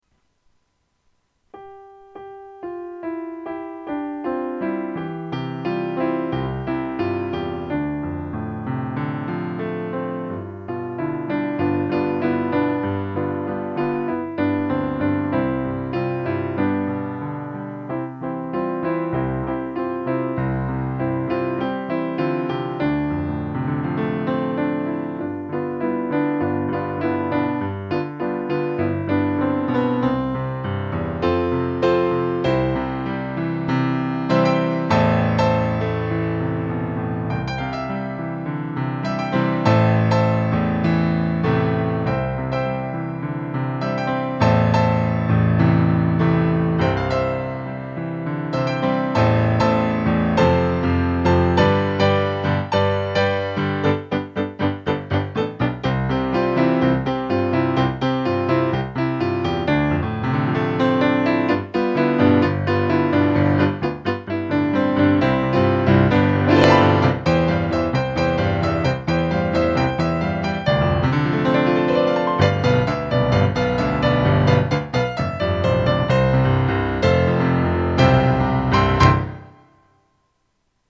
Pro tento týden jsem si pro vás připravil opět několik klavírních doprovodů písniček, které si můžete zazpívat třeba i se sourozenci nebo rodiči.